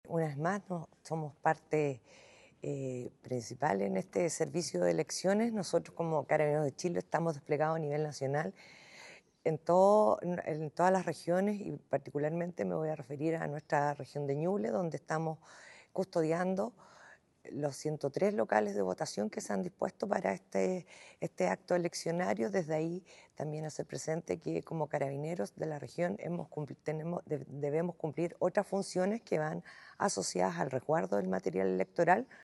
El anuncio se realizó en un punto de prensa efectuado la jornada del sábado, donde participaron el Jefe de la Defensa Nacional en Ñuble, general Patricio Valdivia, el director regional del Servel, Aldo Valenzuela, y la general jefe de zona de Carabineros, Loreto Osses.